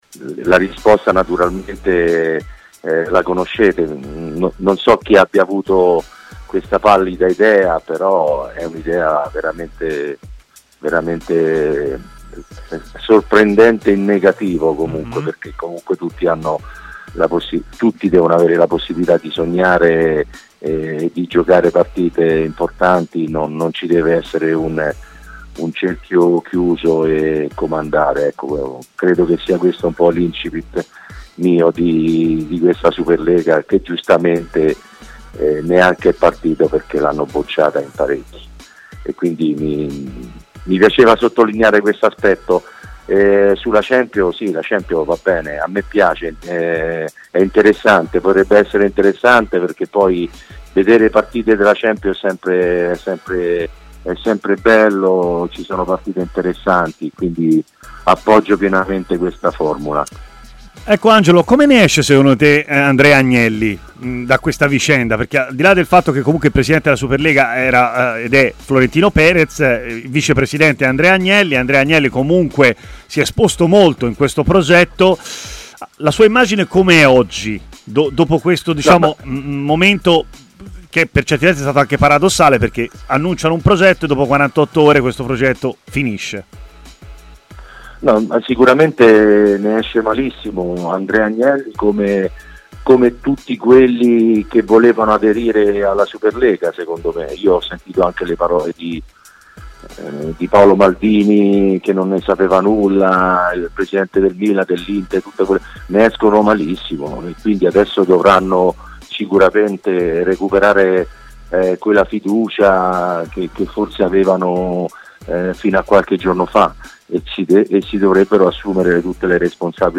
Angelo Di Livio è intervenuto in diretta a TMW Radio alla trasmissione 'Stadio Aperto'.